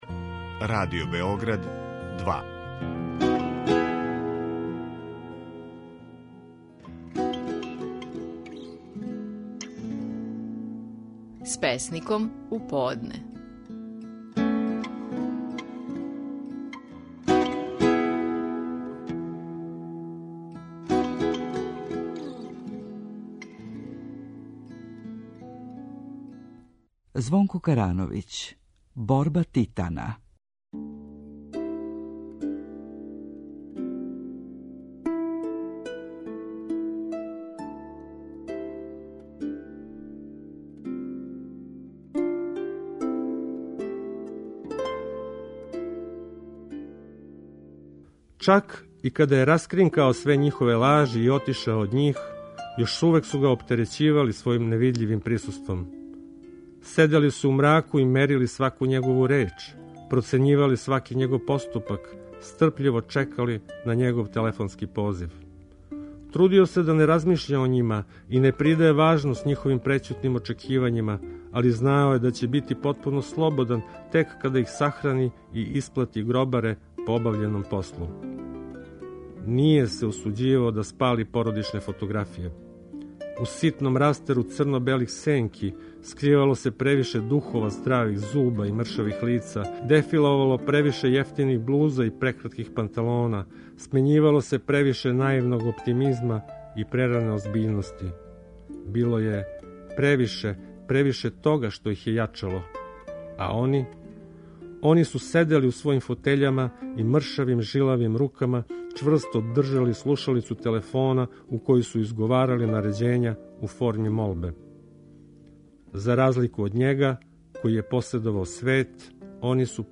Стихови наших најпознатијих песника, у интерпретацији аутора